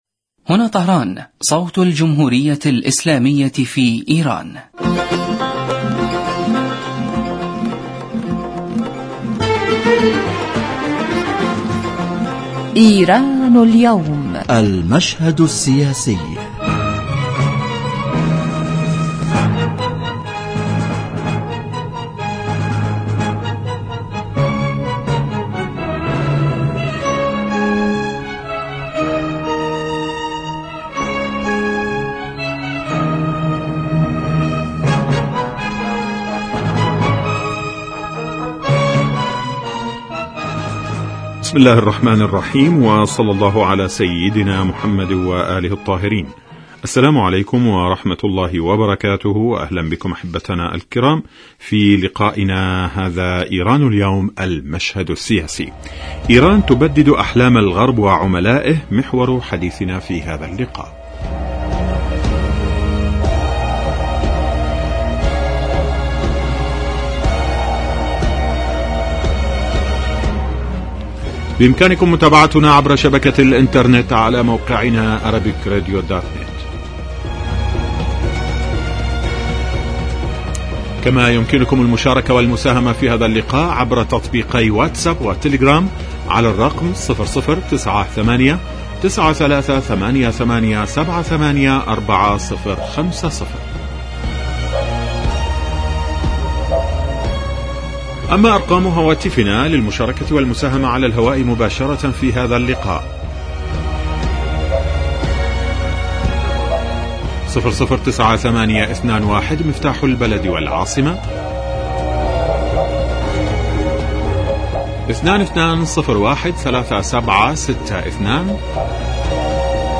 إيران تبدد أحلام الغرب وعملائه Arabic Radio 14 views 31 October 2022 Embed likes Download إذاعة طهران-إيران اليوم المشهد السياسي